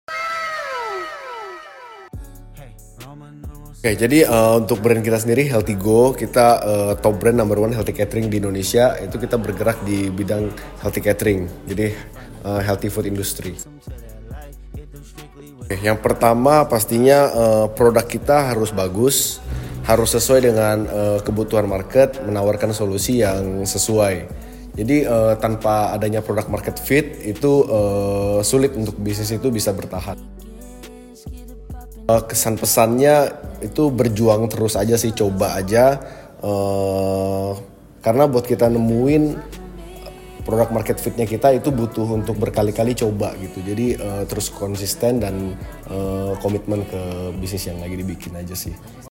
interview eksklusif ini!